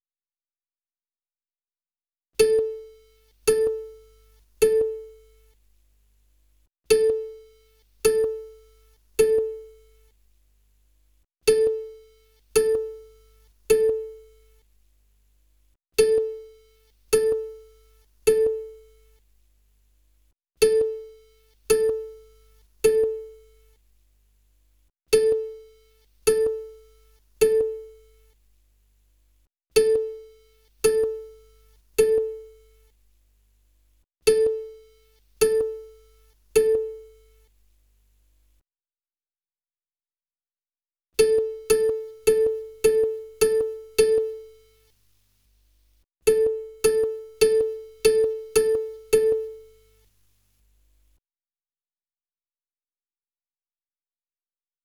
Dolby Atmos Binaural Test File Downloads
* headphones/earbuds only